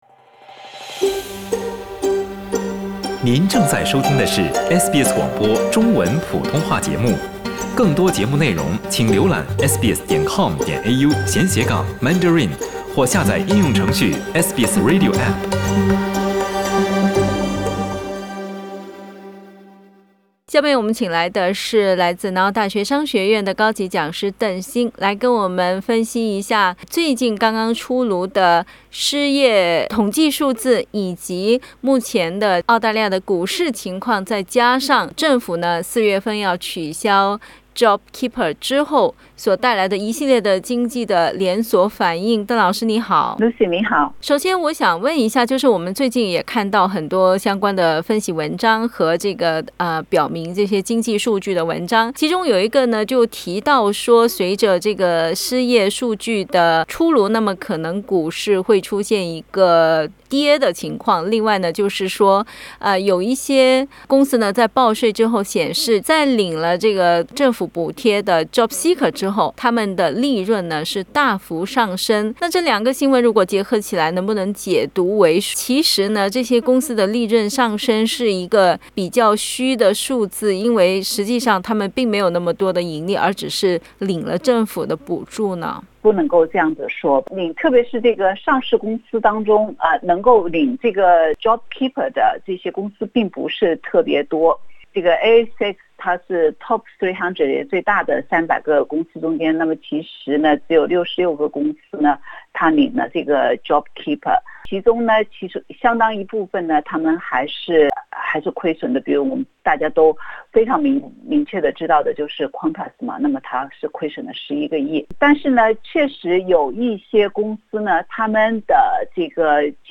（请听采访。本节目为嘉宾观点，不代表本台立场） 澳大利亚人必须与他人保持至少 1.5 米的社交距离，请查看您所在州或领地的最新社交限制措施。